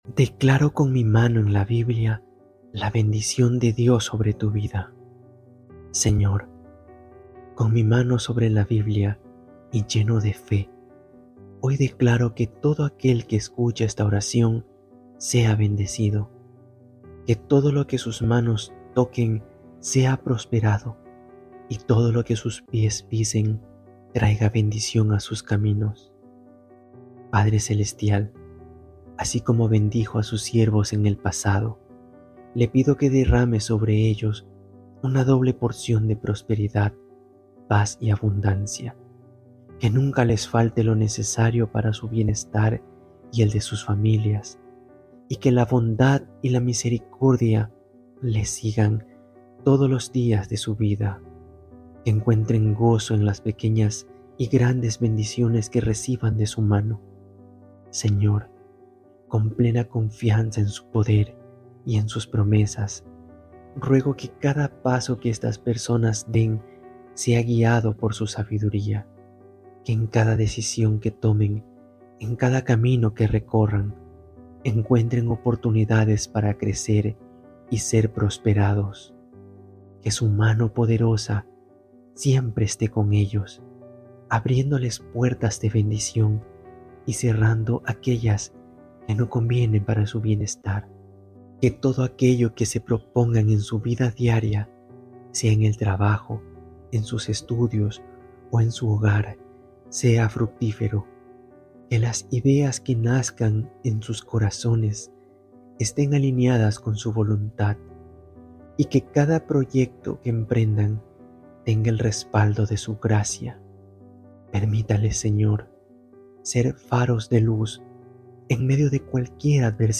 Oración de bendición